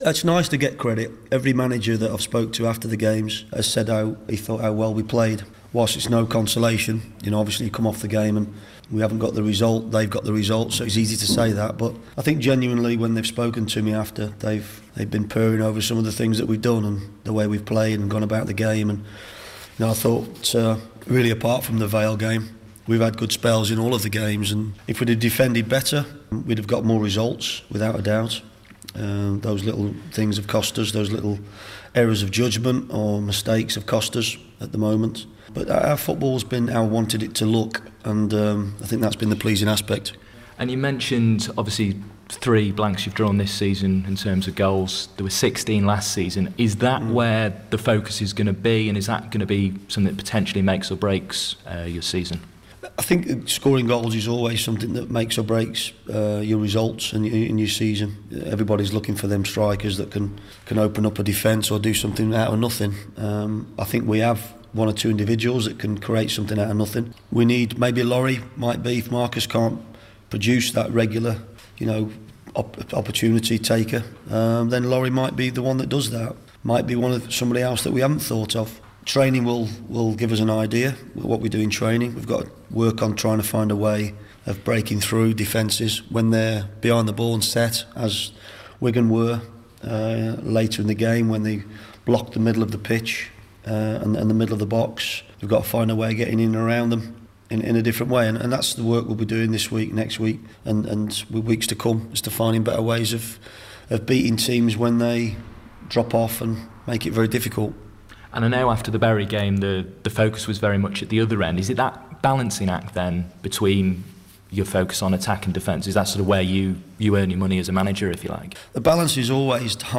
BBC Radio Stoke Sport at Six interview